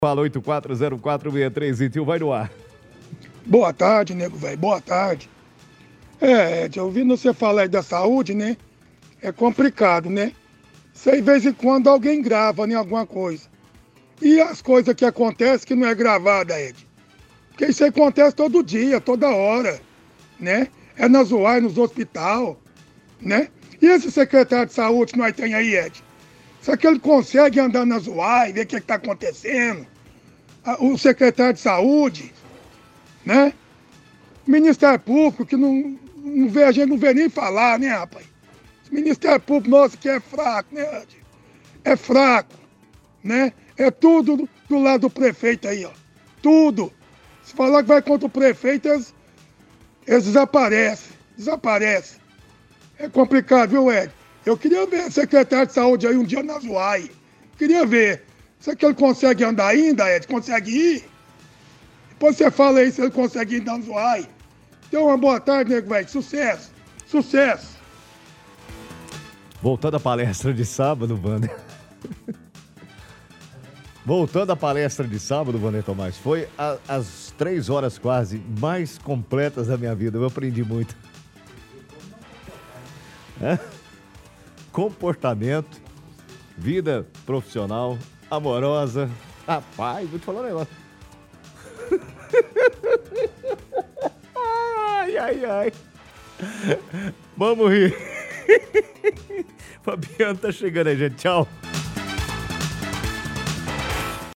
Ligação Ouvinte – Saúde
Ligação-Ouvinte-Saúde.mp3